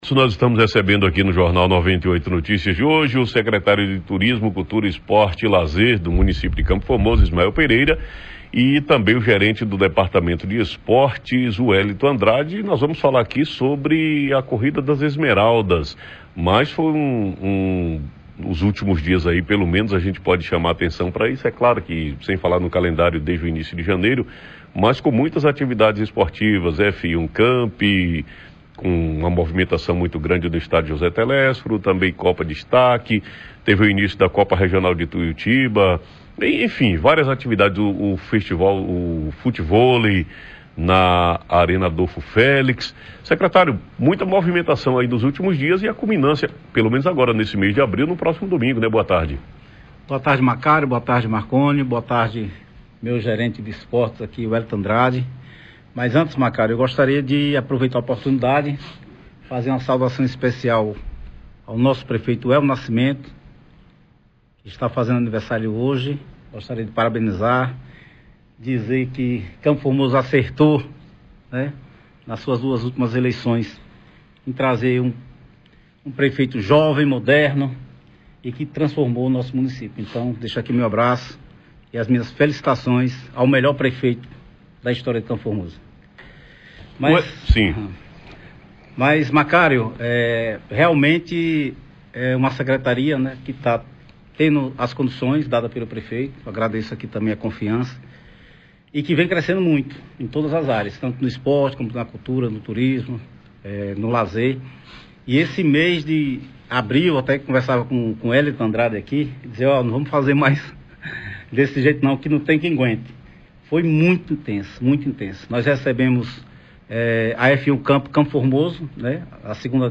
Secretário de cultura, turismo e esporte do município de Campo Formoso e organizadores da 1ª Corridas das Esmeraldas
entrevista-1.mp3